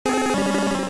Cri de Salamèche K.O. dans Pokémon Diamant et Perle.